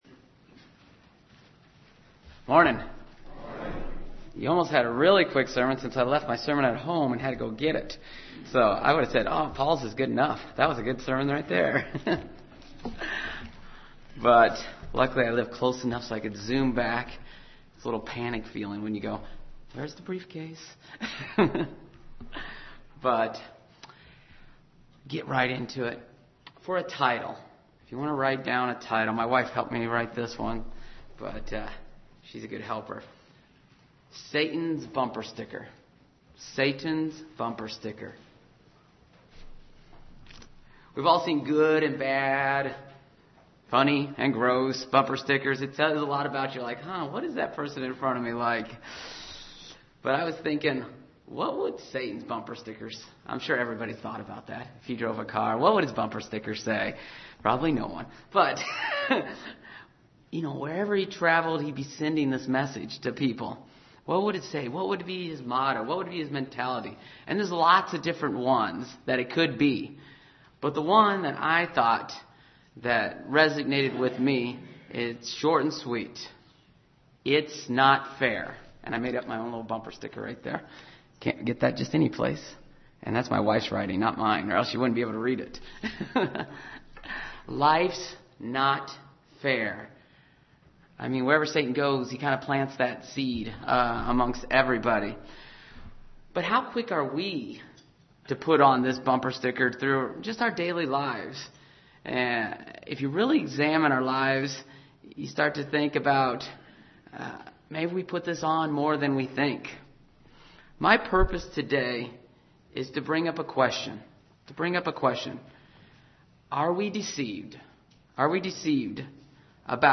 Given in Ft. Wayne, IN
UCG Sermon Studying the bible?